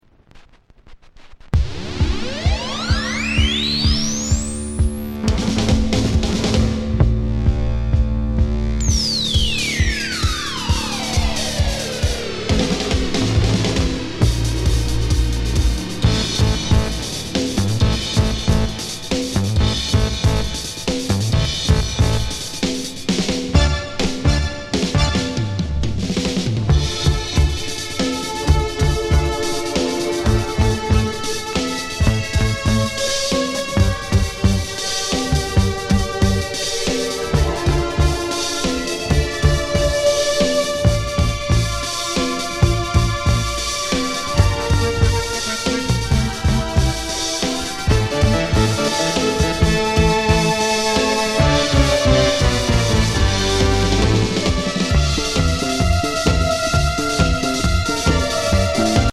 SFテーマ・シンセサイザー化アルバム